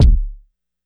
Kick (Day 'N' Nite).wav